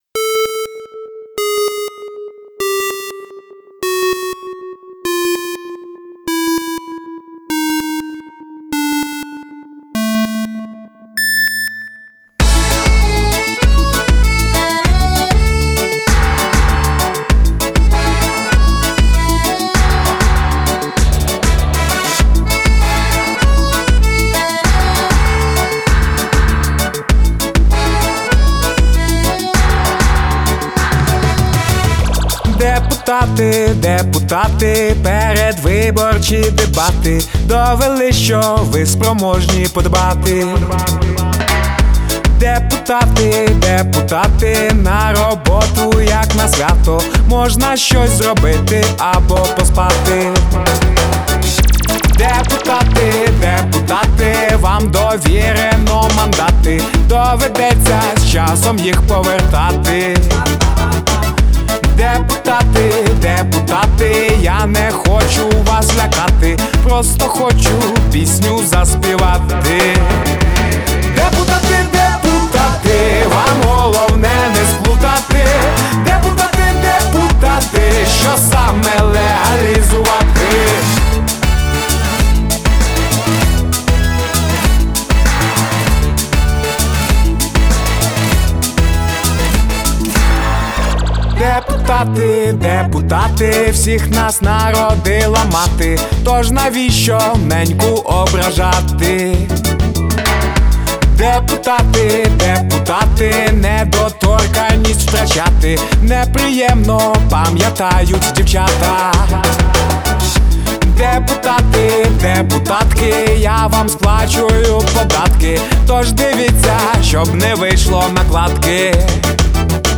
Регги